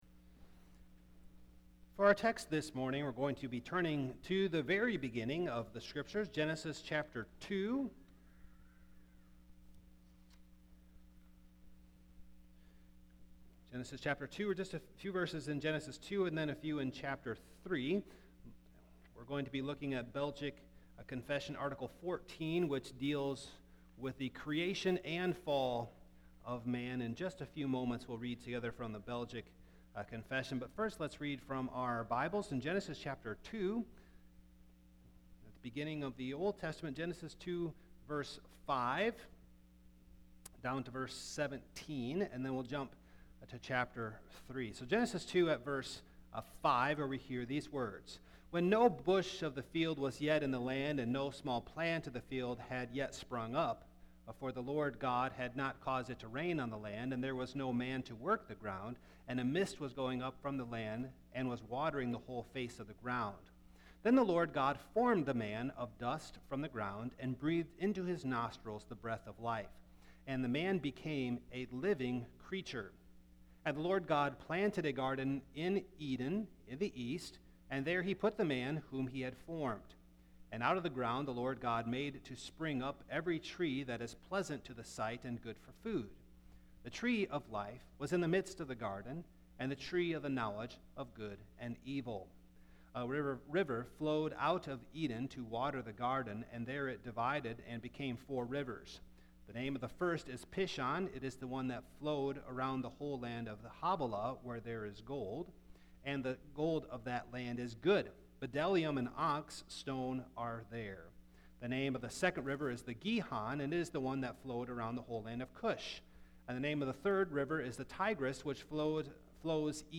Passage: Gen. 2:5-17; 3:1-7 Service Type: Morning